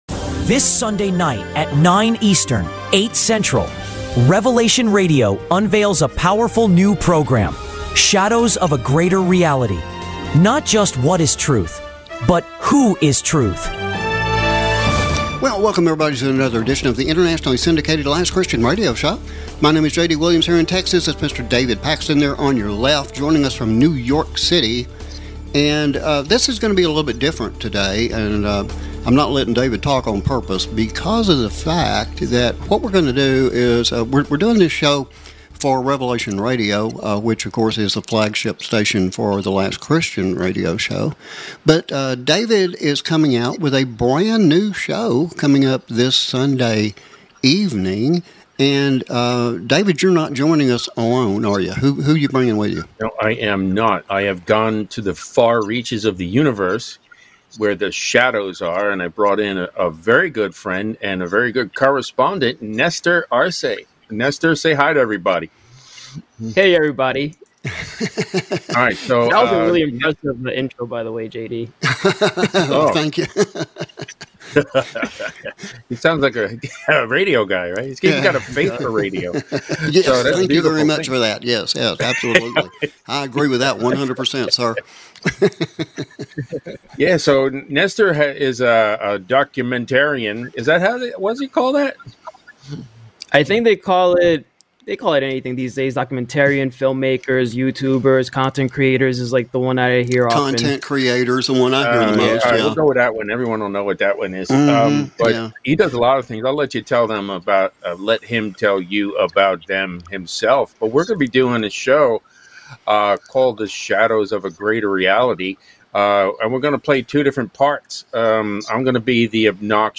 Shadows of a Greater Reality is not just another radio show chasing aliens, ghosts, and conspiracy theories.